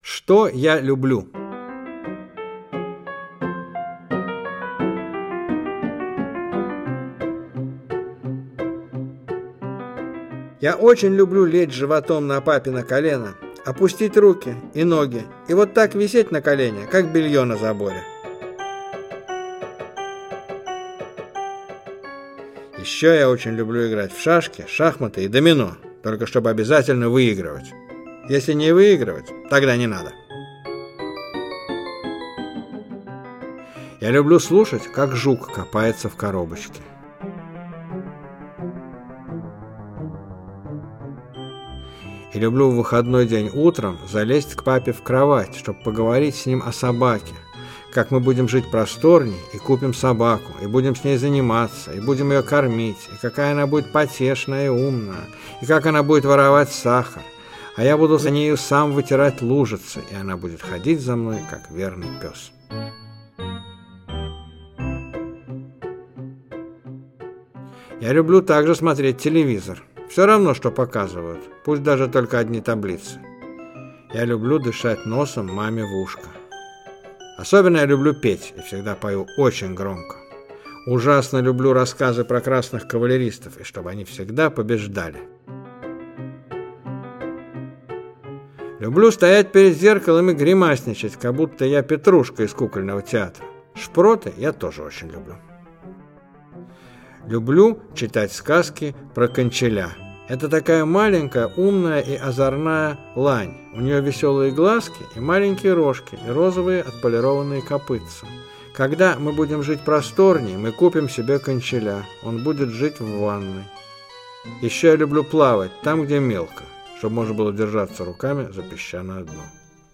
Аудиокнига Он живой и светится | Библиотека аудиокниг
Aудиокнига Он живой и светится Автор Виктор Драгунский Читает аудиокнигу Мария Голубкина.